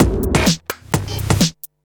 Electrohouse Loop 128 BPM (12).wav